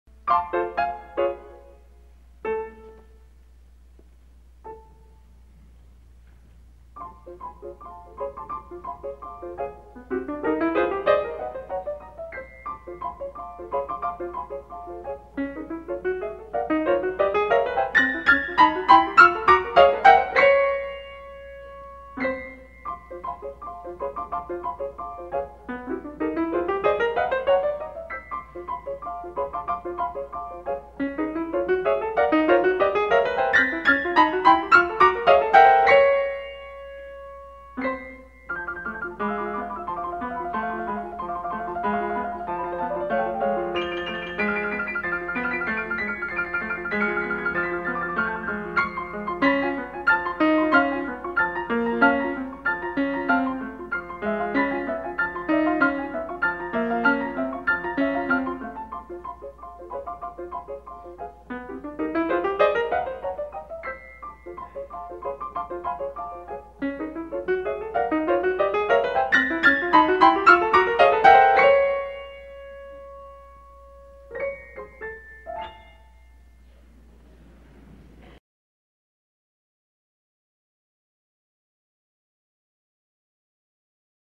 Произведение состоит из 10 фортепианных пьес (картин), разделённых музыкальными променадами, которые играют роль связующего элемента между частями.
«Балет невылупившихся птенцов» – пьеса вдохновлена весёлыми театральными эскизами Гартмана к балету Юрия Гербера «Трильби». Тема резко отличается от предшествующего «Быдла», представляя лёгкое и игривое скерцо, воплощая комичный образ неуклюжих птенцов.